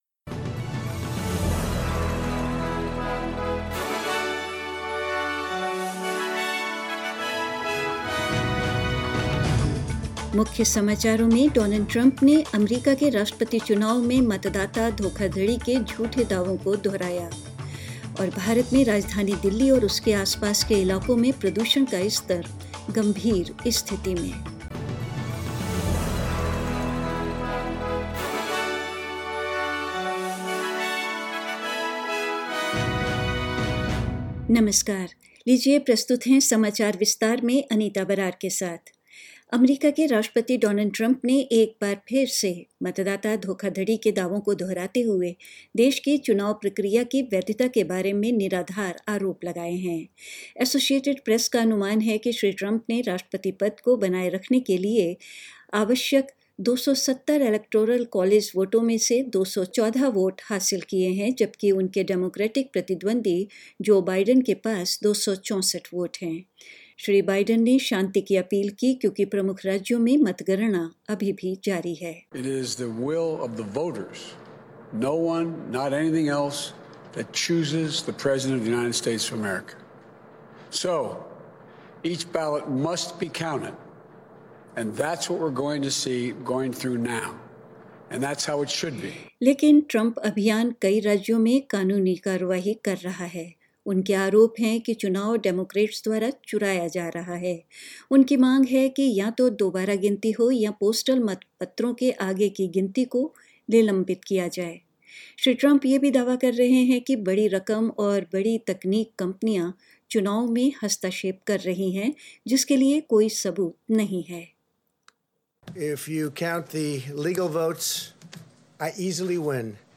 News in Hindi 6th November 2020